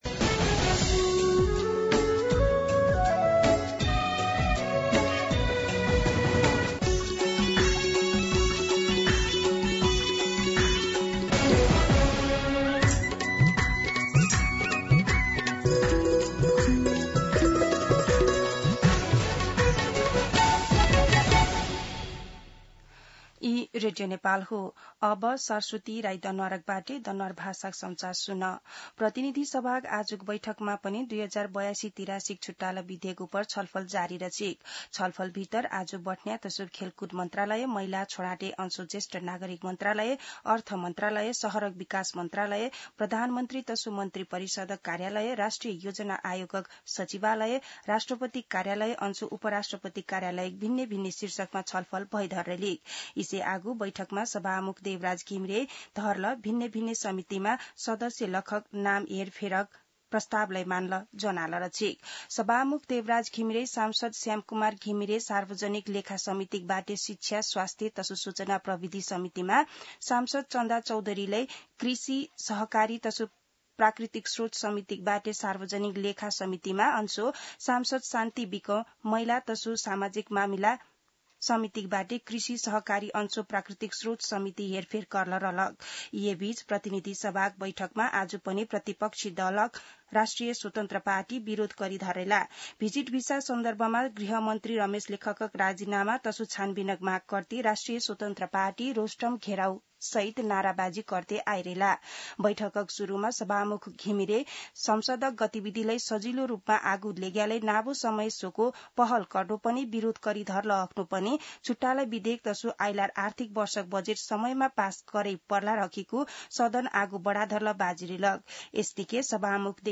दनुवार भाषामा समाचार : ८ असार , २०८२
Danuwar-News-08.mp3